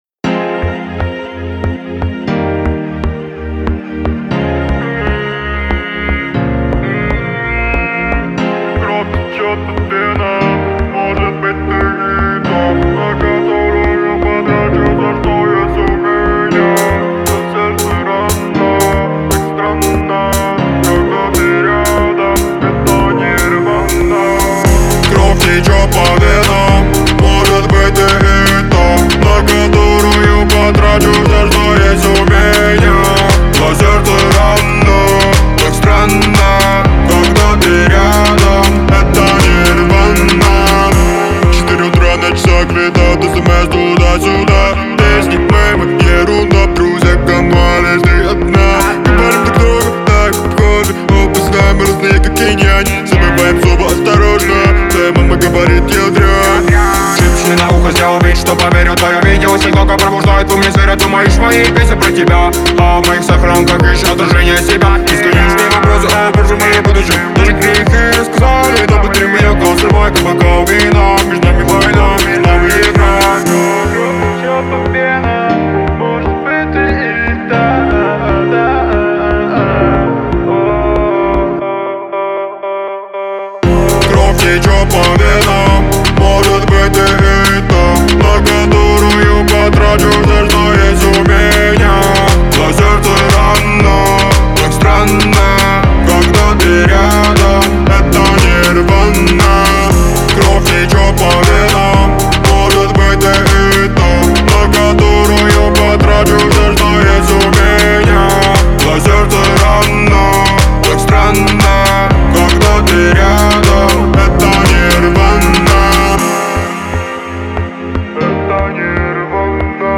гитарными рифами и эмоциональным вокалом
создавая атмосферу уязвимости и глубокой introspektivности.